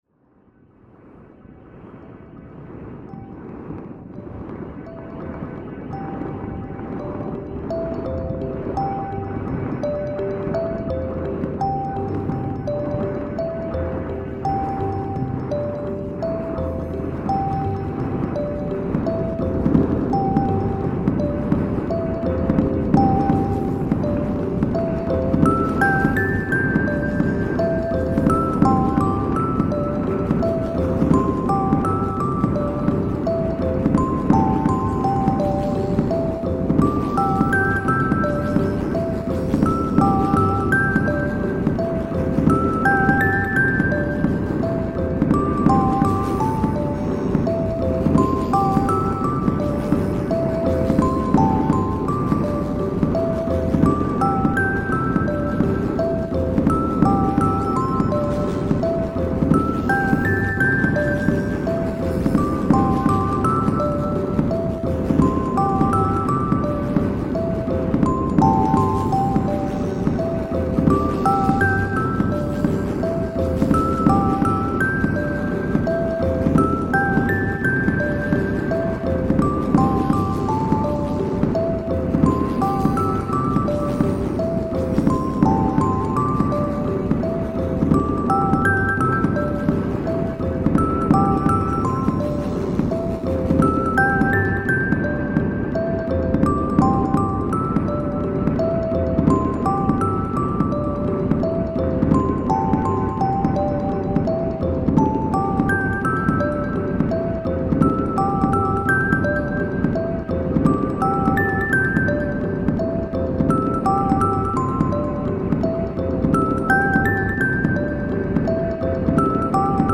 I took a lullaby theme as a counterpoint to the noise. Firework sound samples provide rhythmical element. Chaos stabilises as the recording ends, to create a final sense of calm and serenity.
Shanghai street festival reimagined